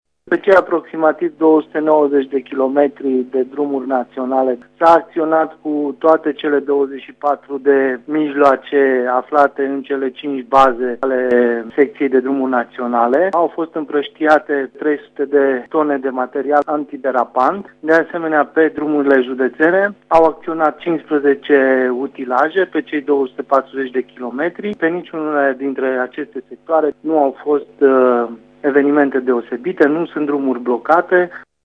Prefectul de Covsna, Sebastian Cucu, a declarat că pe cele 530 de kilometri drumuri naţionale şi judeţene din Covasna se circulă fără probleme, carosabilul fiind umed: